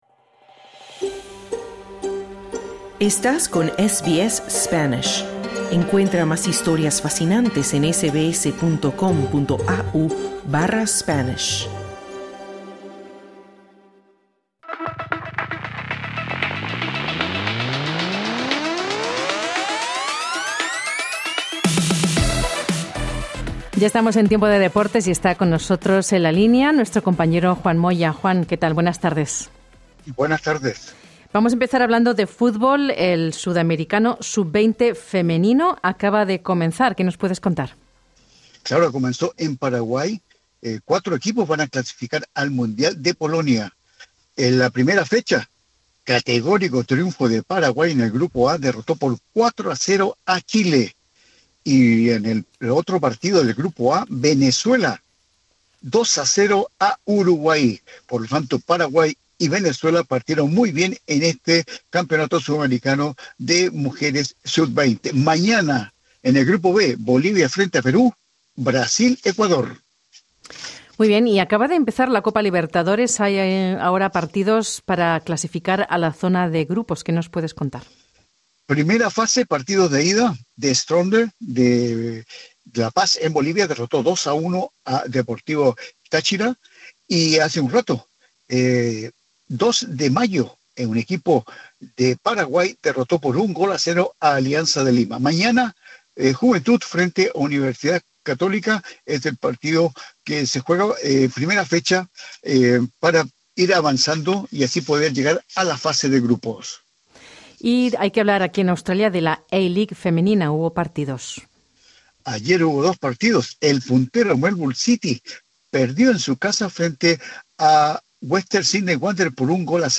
Noticias deportivas